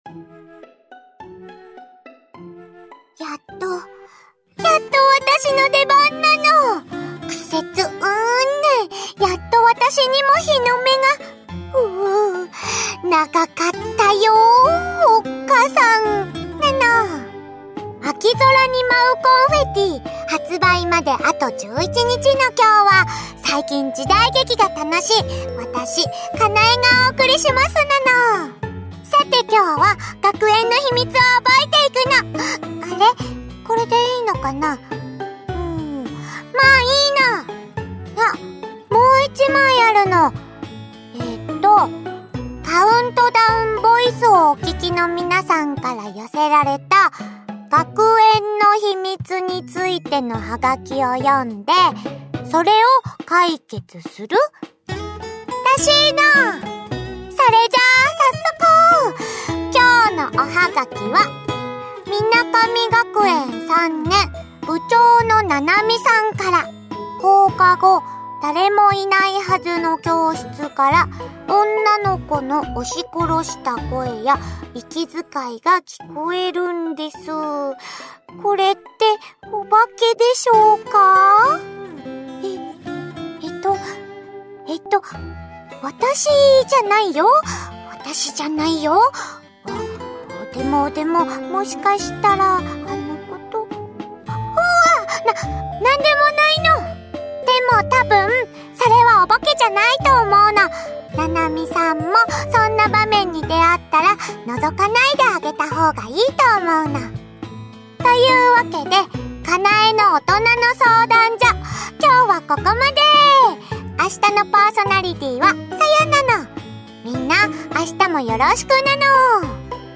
カウントダウンボイス（11日前）を公開しました。
countdown_voice_11.mp3